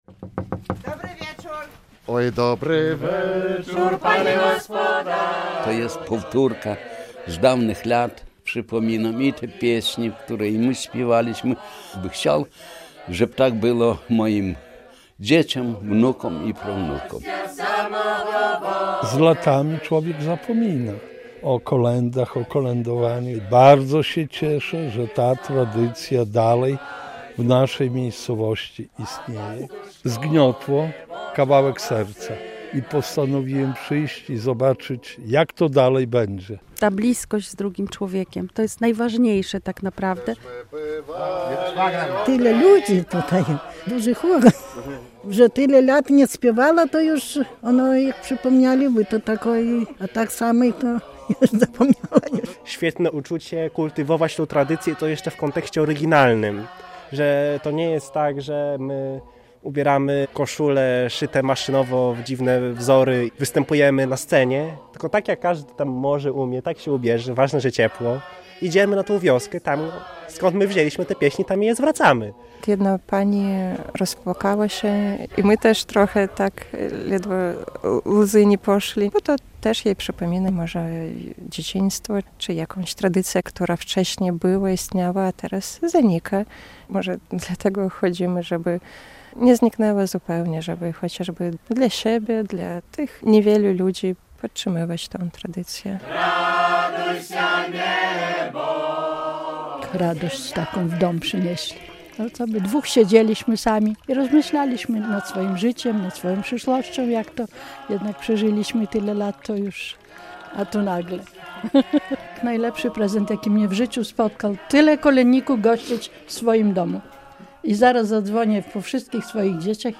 To bożonarodzeniowa tradycja - kolędnicy odwiedzają domy w podlaskich wsiach